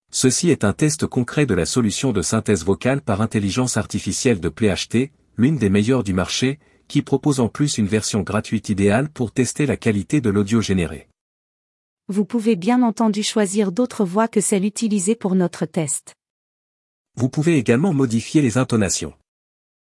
Test-de-PlayHT-Outil-de-generation-de-voix-par-IA-LEPTIDIGITAL.mp3